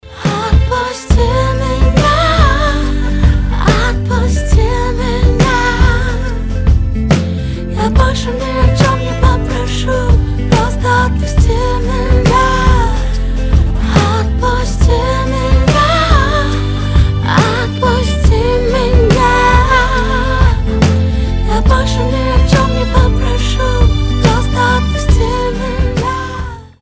• Качество: 320, Stereo
спокойные
RnB
soul
нежные